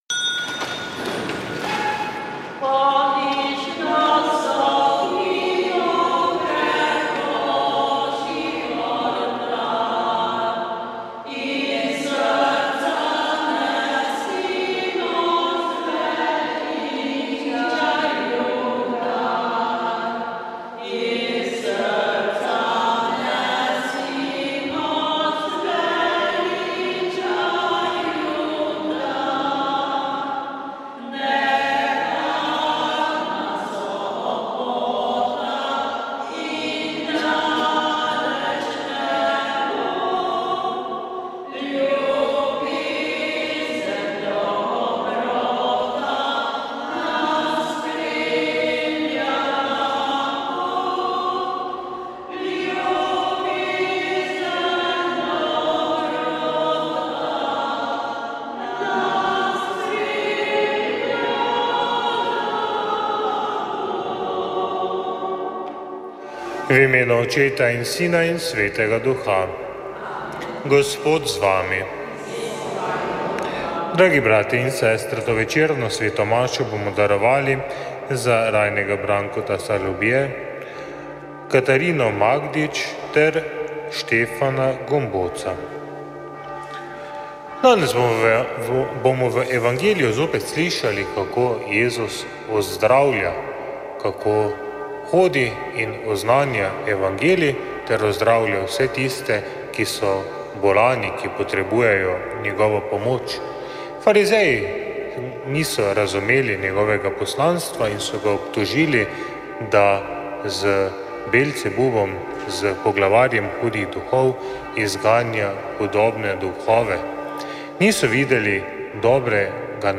Sveta maša
Sv. maša iz stolne cerkve sv. Nikolaja v Murski Soboti 9. 7.